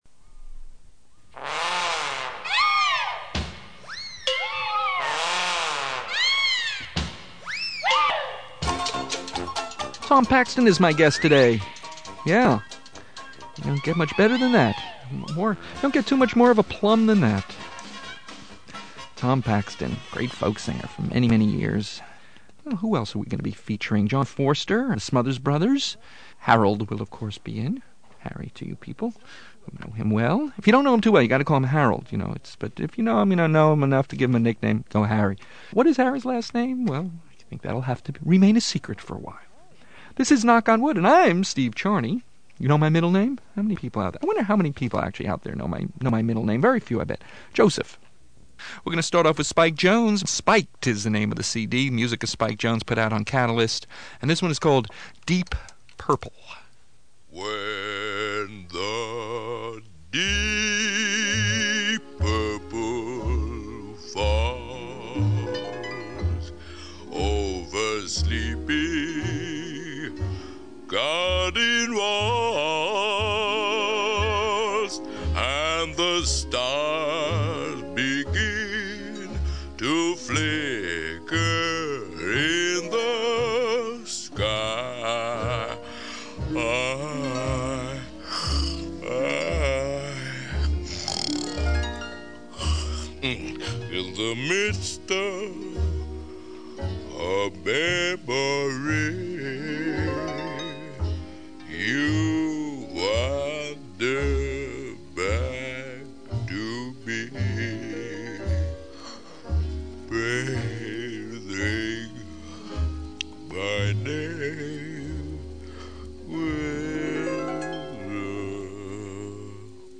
Comedy Show